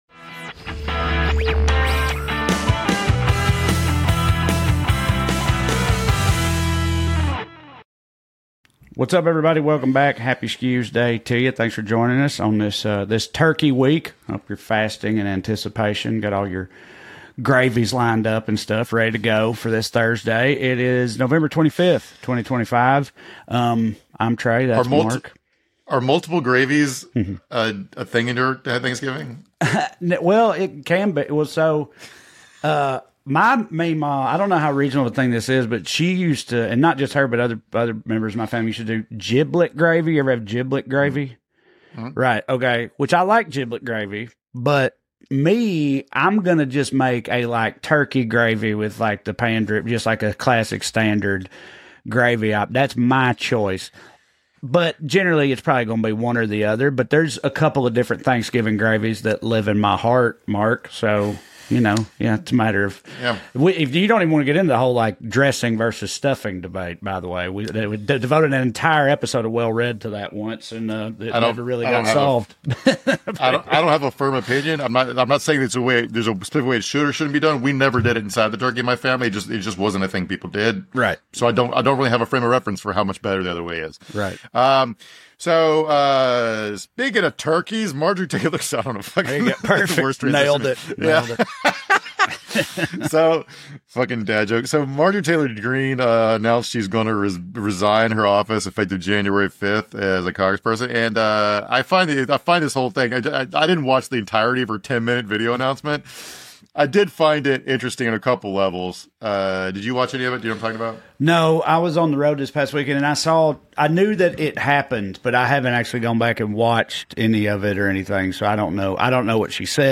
The AI nerds are angling for a government bailout for a crash that hasn’t happened yet, so your Social Security can fund chatpots that give you recipes with rocks in them. Then we talk with Tennessee State Rep. Aftyn Behn about her run for Congress in next week’s special election, and what it’s like becoming a national target for attacks just because the race is closer than anyone thought it would be.